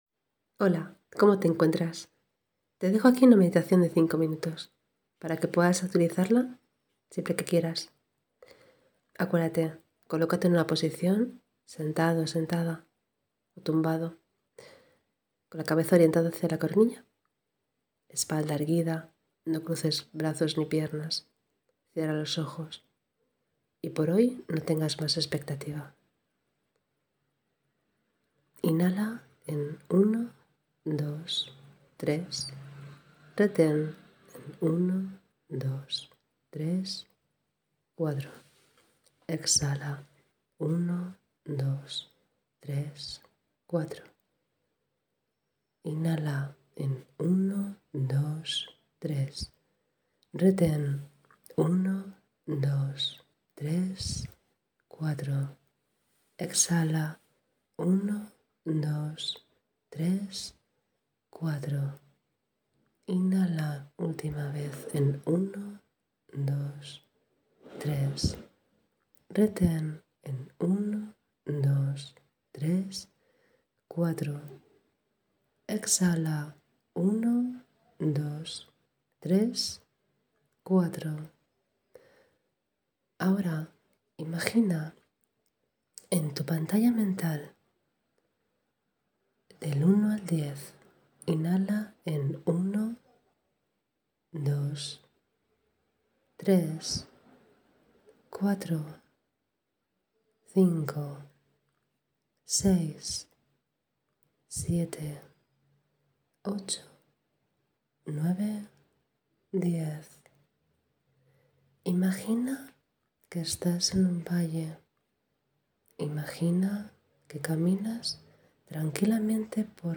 meditacioin-cinco-minutos-overthinking-AoPv5X2llzu14qlo.mp3